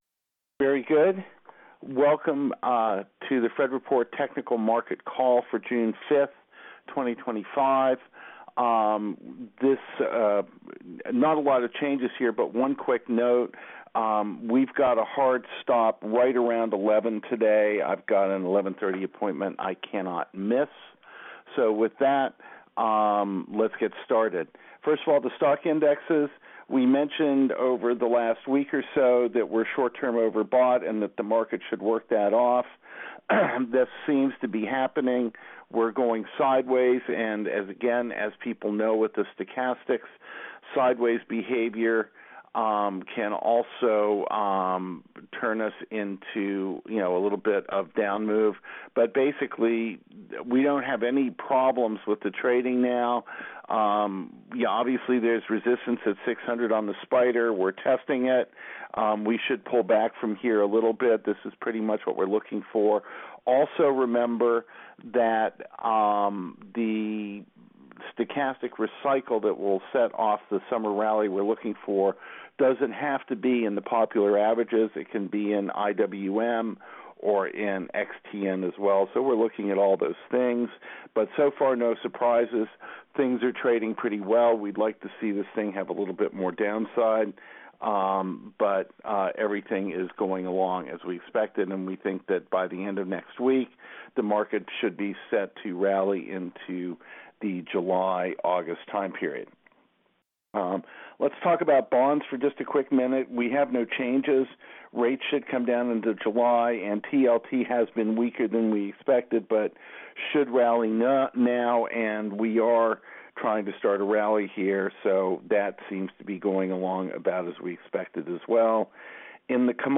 The Fred Report - Conference Call June 5, 2025